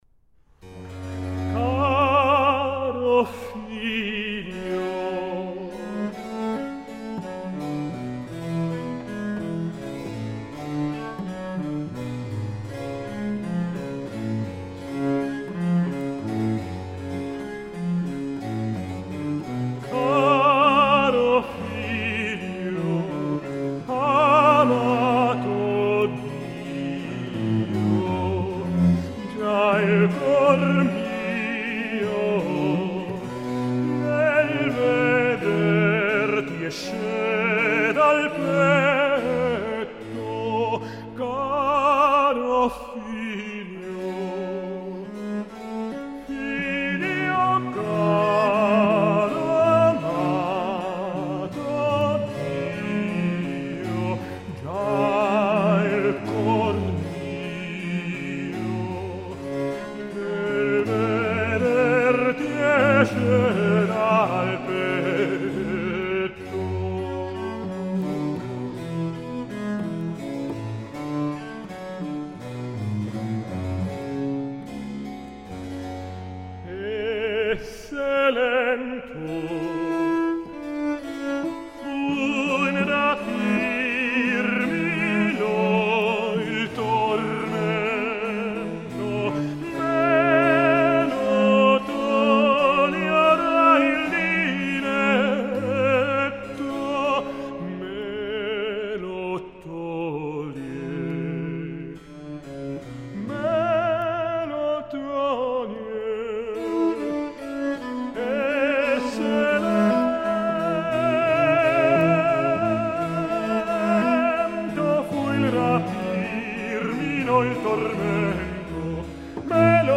Aria: “Così la tortorella” (S. Giovanni)